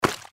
Index of /server/sound/npc/gecko/foot
fs_gecko_r01.mp3